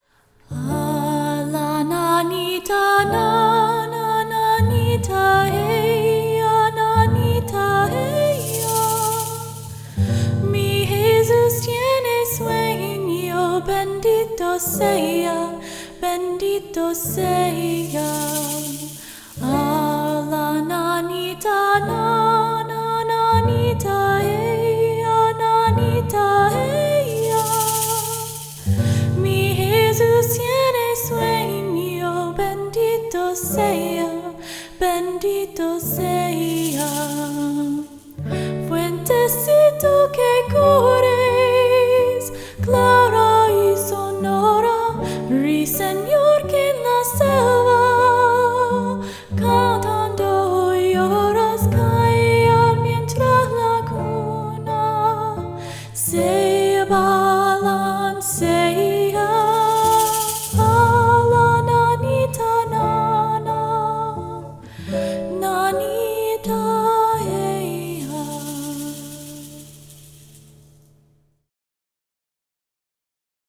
Another Lullaby…
I always found this song very calming.